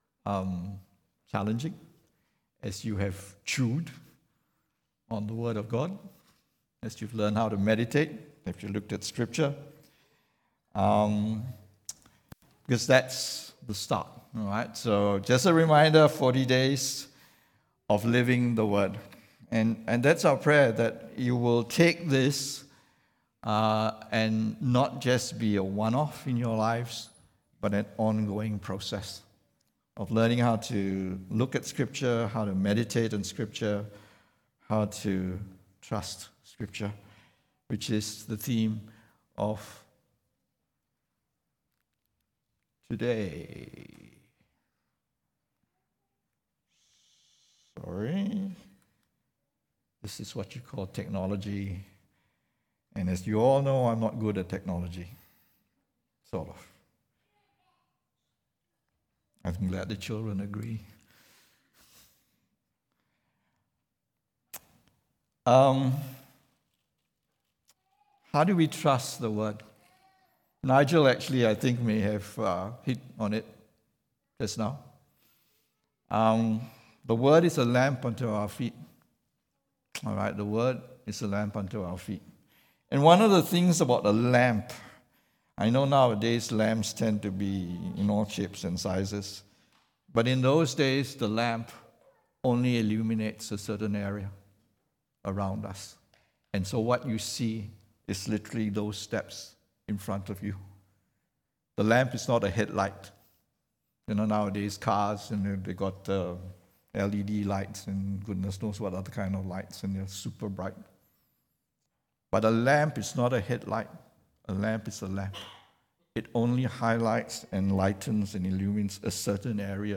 English Sermons | Casey Life International Church (CLIC)
English Worship Service - 26th February 2023